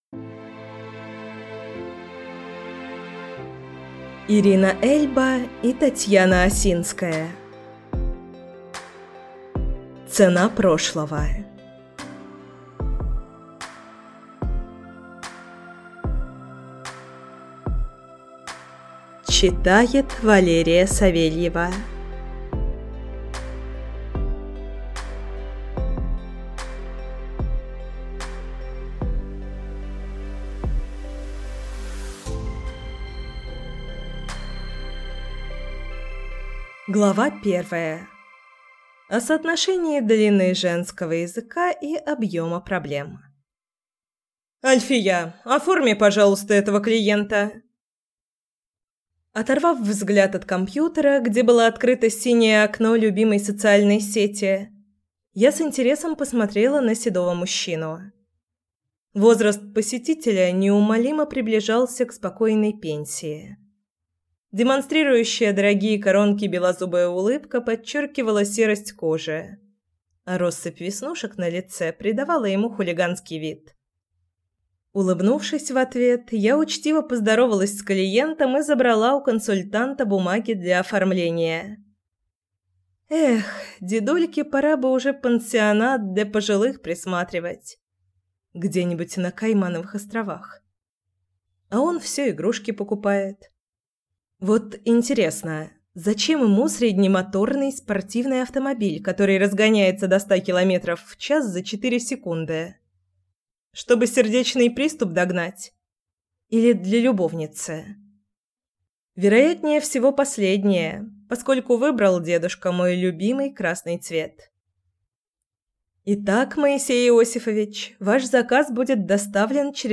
Аудиокнига Цена прошлого | Библиотека аудиокниг
Прослушать и бесплатно скачать фрагмент аудиокниги